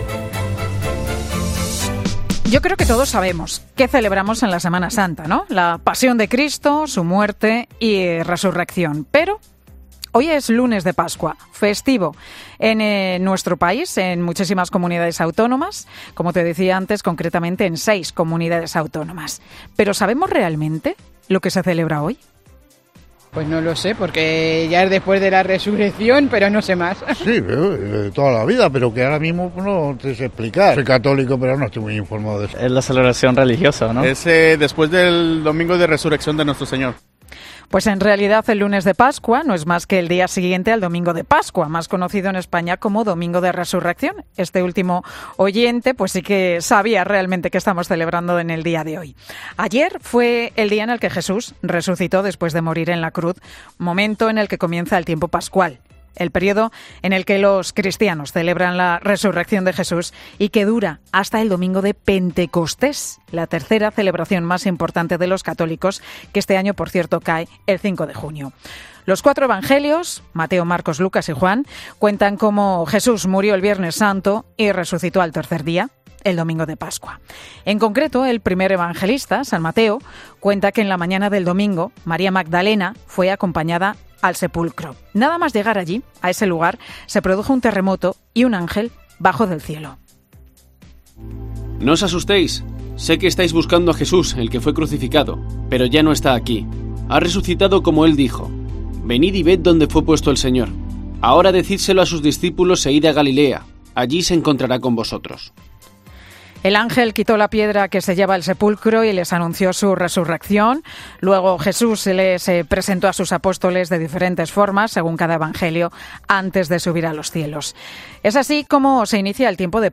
Hablamos en COPE con uno de los vicarios de la archidiócesis de Madrid en el segundo día de Pascua